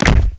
jumpland.wav